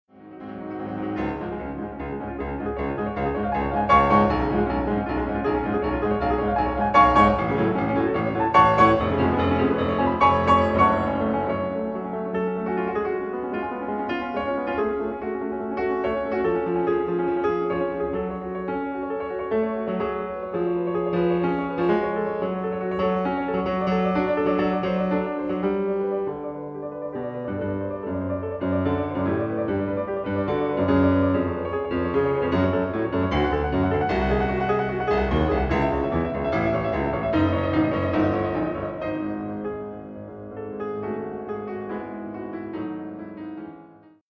Qualité suffisante pour apprécier l'exactitude d'un montage, mais rien à voir avec l'original en 24/96 8)